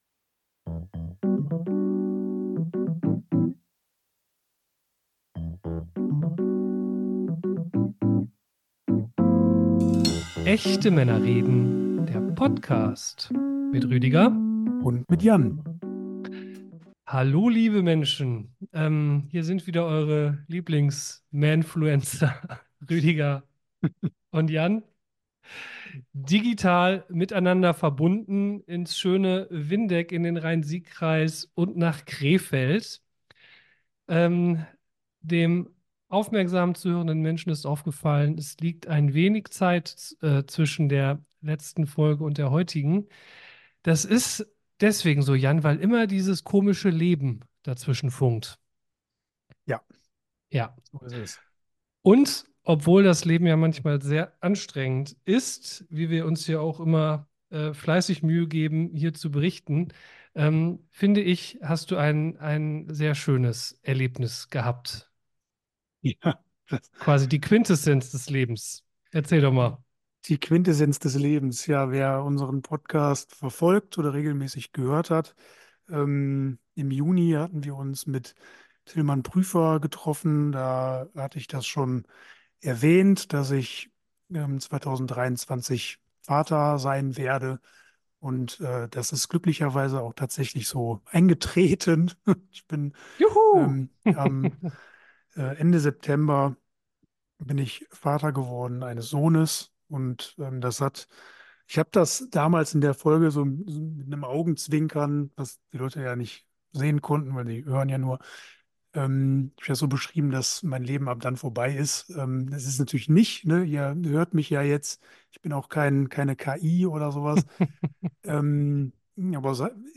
Die beiden reden sich schnell heiß, wenn es um Männerbilder von rechts geht.